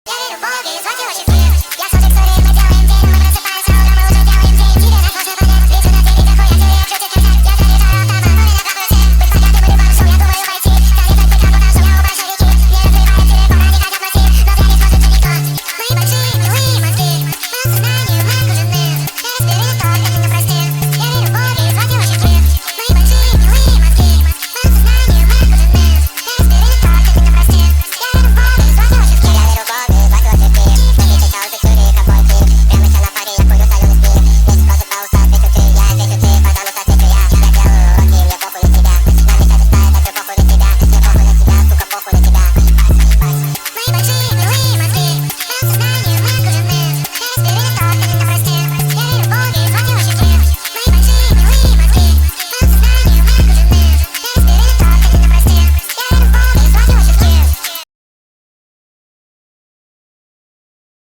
Зарубежная